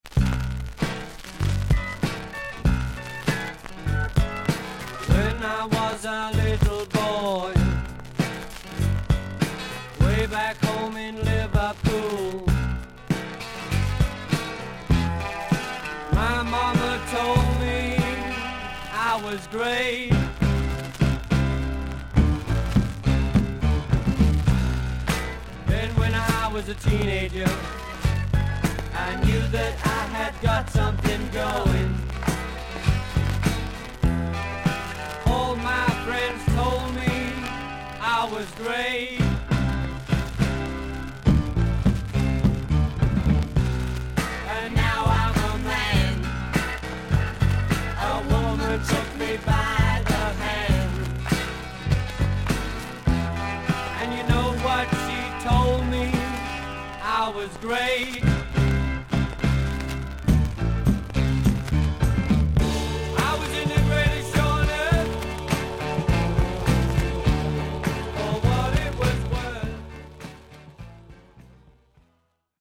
イギリス盤 / 12インチ LP レコード / ステレオ盤
少々軽いパチノイズの箇所あり。全体的に少々サーフィス・ノイズあり。音はクリアです。
ステレオ盤... オルトフォン SPU-GT